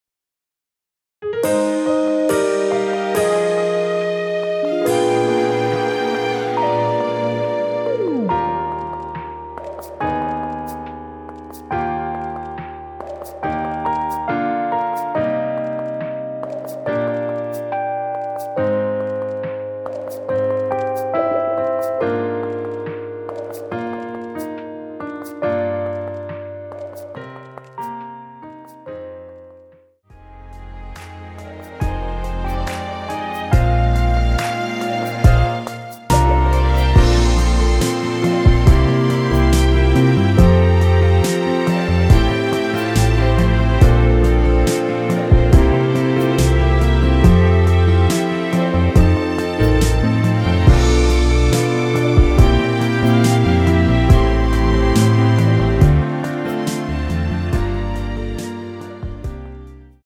원키에서(+1)올린 MR입니다.
Eb
앞부분30초, 뒷부분30초씩 편집해서 올려 드리고 있습니다.